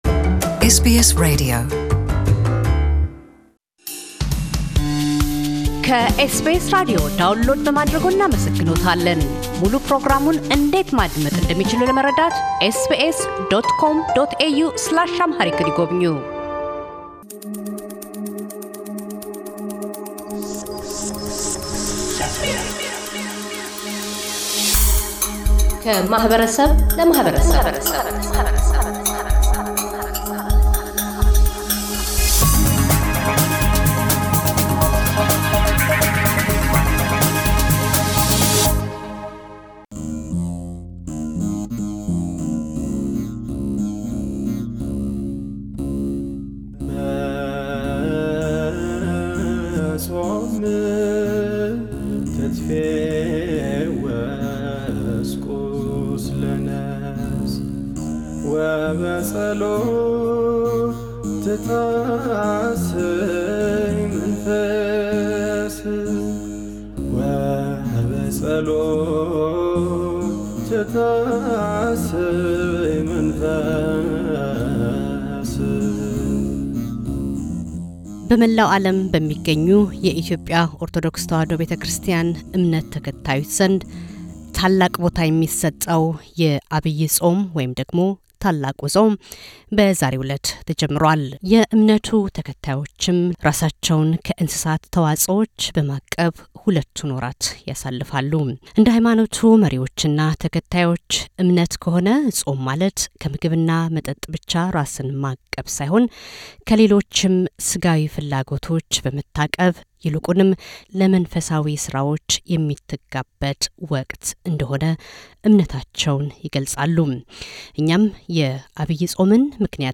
ብፁዕ አቡነ ጴጥሮስ - የኢትዮጵያ ኦርቶዶክስ ተዋሕዶ ቤተክርስቲያን የምሥራቅ አውስትራሊያና ኒውዚላንድ አኅጉረ ስብከት ሊቀ ጳጳስና የሲኖዶስ አባል፤ ስለ ዐቢይ ጾም ትርጓሜና አጿጿም ይናገራሉ።